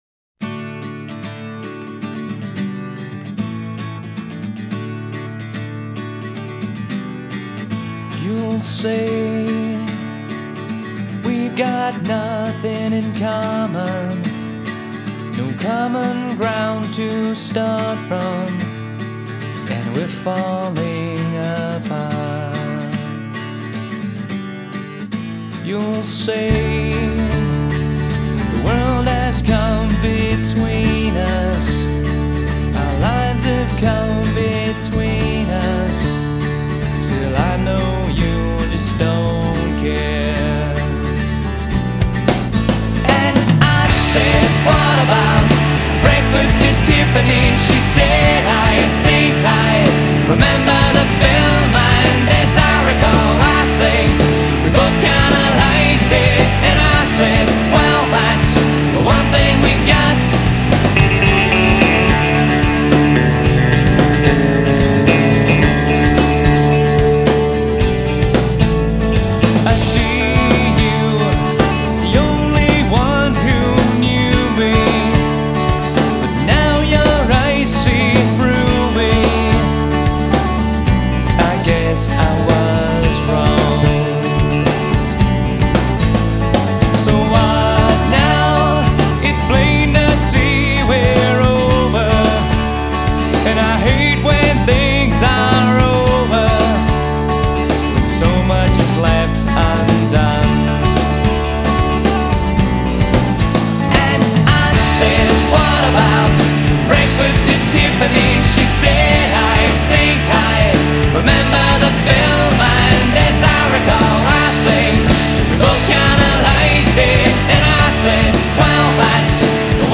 American alternative rock band